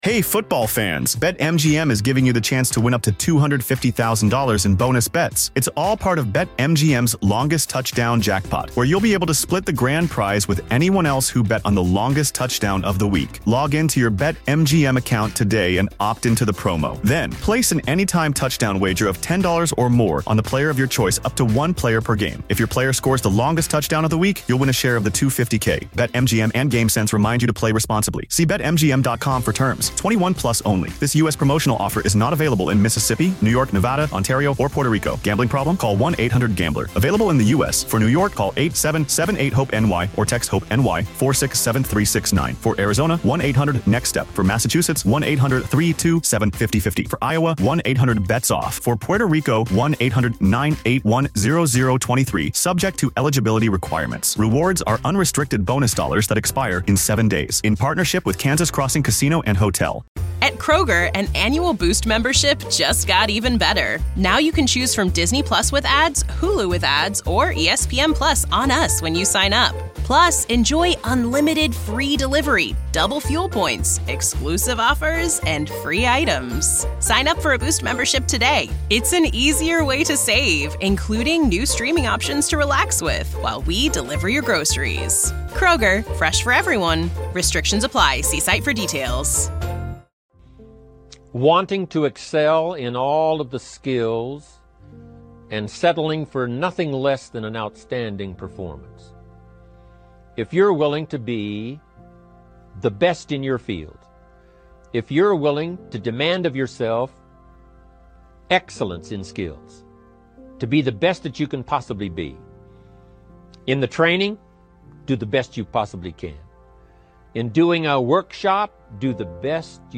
Set Goals And Don't Miss Anything - Powerful Motivational Speech.